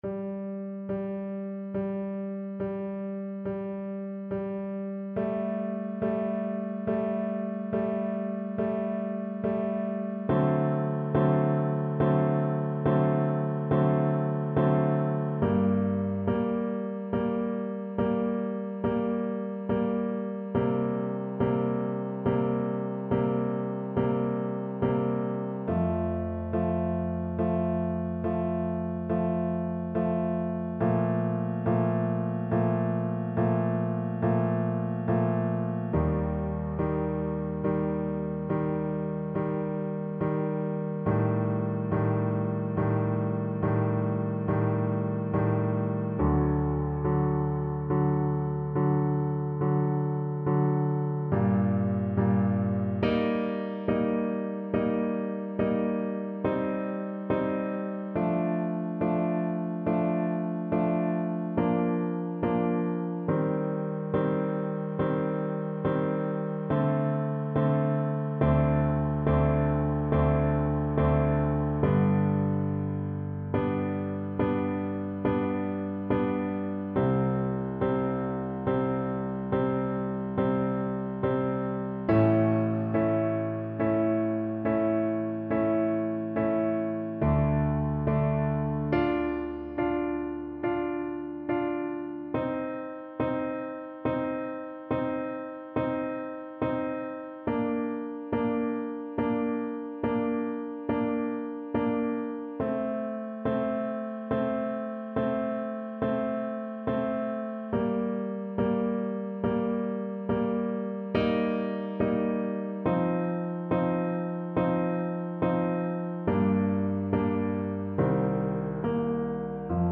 Trumpet version
3/4 (View more 3/4 Music)
Adagio =45
Trumpet  (View more Intermediate Trumpet Music)
Classical (View more Classical Trumpet Music)